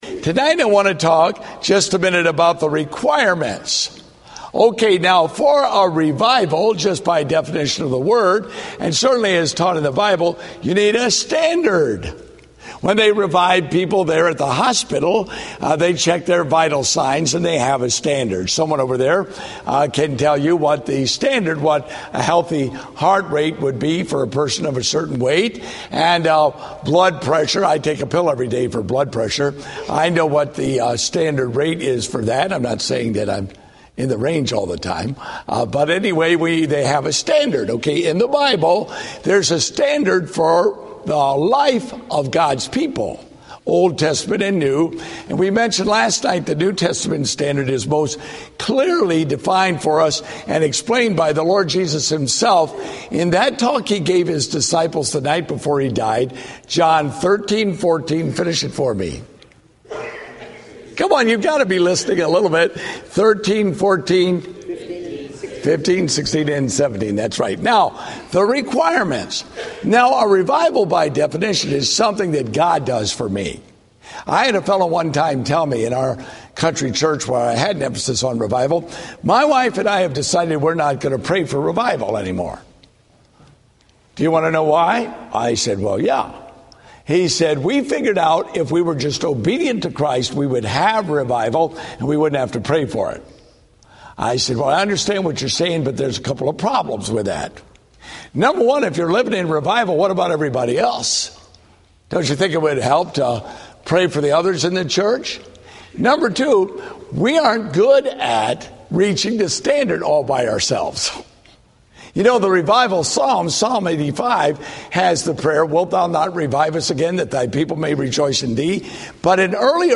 Date: October 22, 2013 (Revival Meeting)